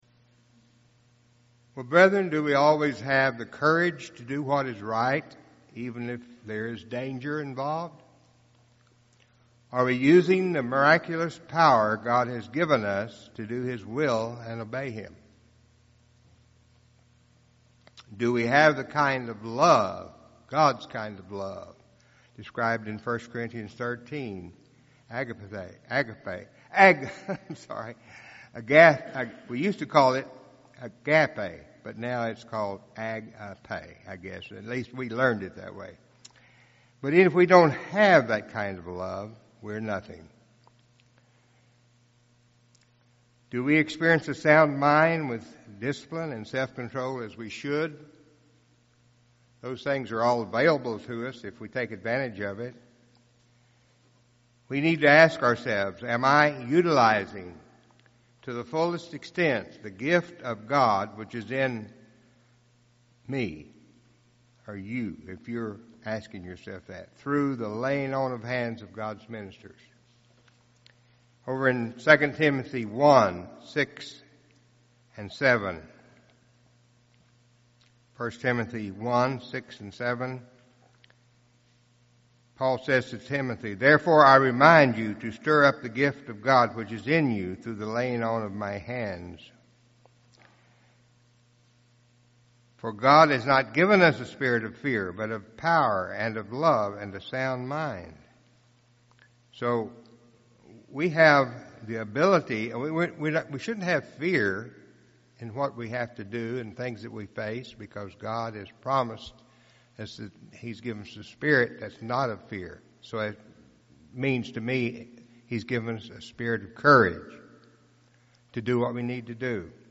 Sermons
Given in Tulsa, OK